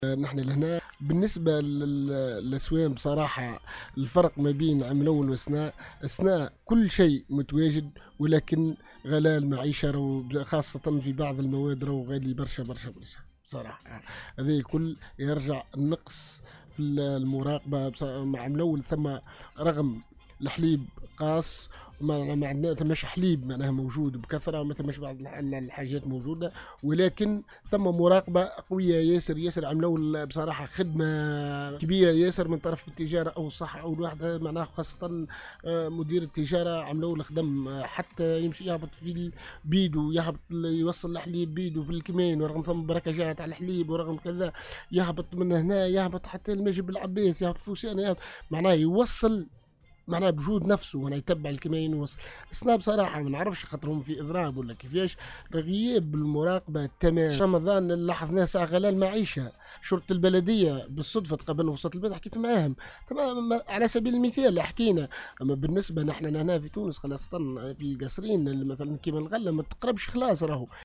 خلال حضوره في برنامج حديث قصارنية بإذاعة السيليوم أف أم